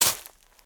decorative-grass-09.ogg